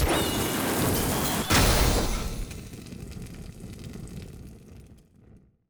sfx-tft-skilltree-ceremony-fire-element-enter.ogg